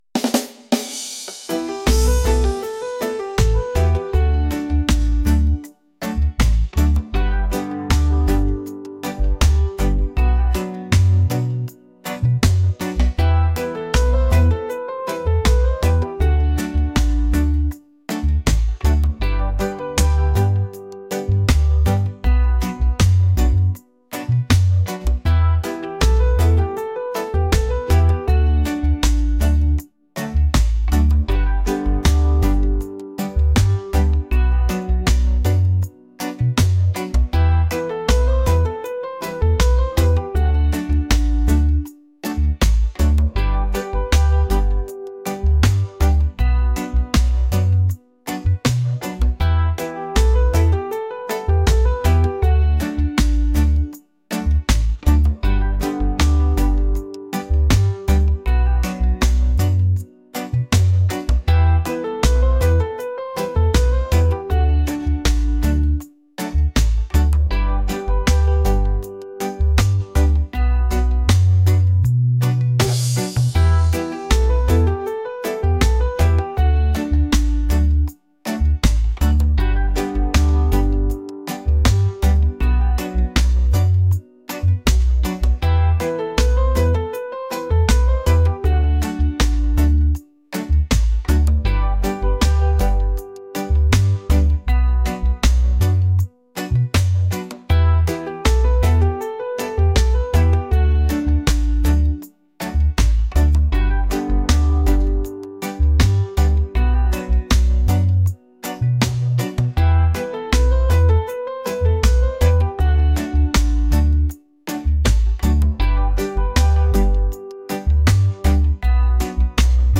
laid-back | reggae | romantic